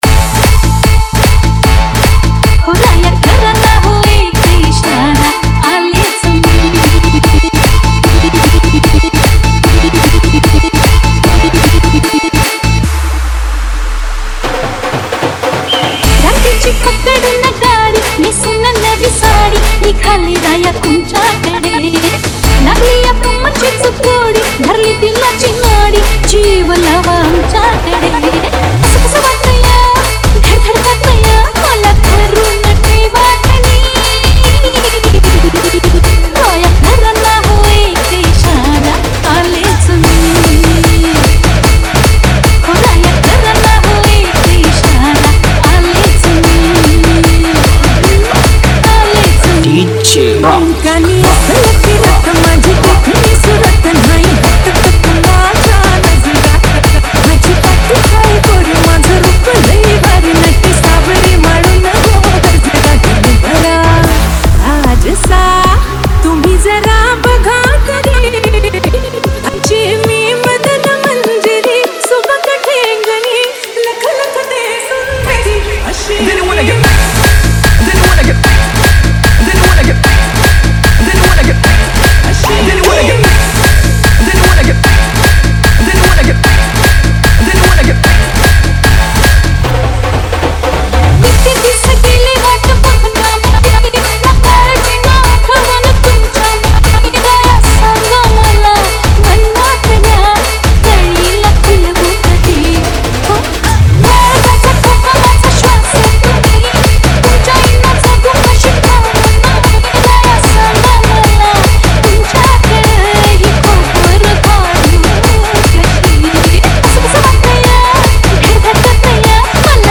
• Category:Marathi Single